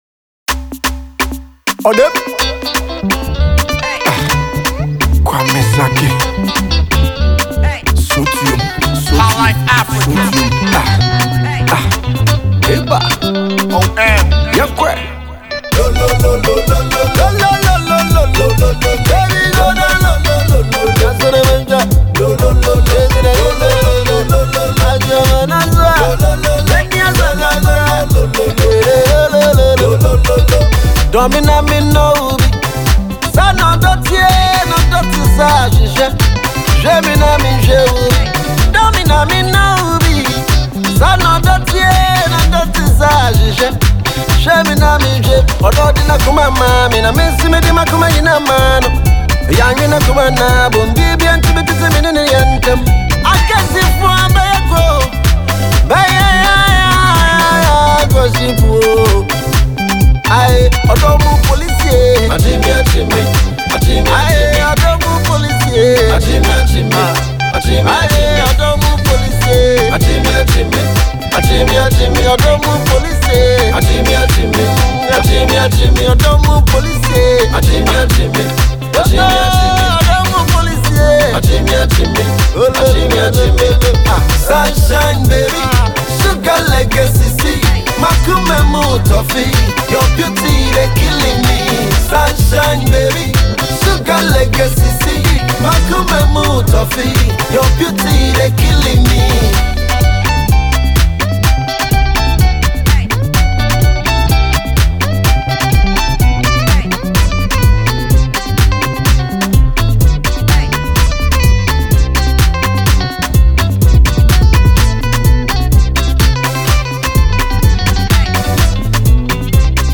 New jam by talented Highlife singer